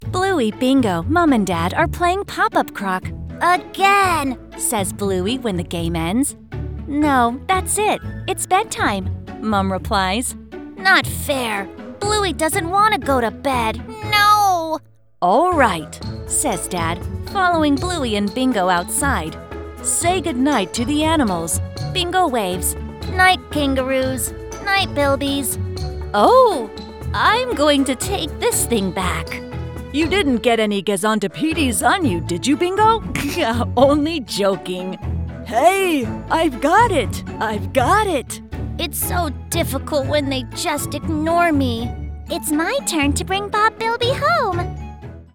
Female
English (American)
Audiobooks
Neumann 103
Whisperroom Booth